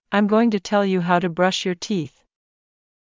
ｱｲﾑ ｺﾞｰｲﾝｸﾞ ﾄｩ ﾃﾙ ﾕｳ ﾊｳ ﾄｩ ﾌﾞﾗｯｼｭ ﾕｱ ﾃｨｰｽ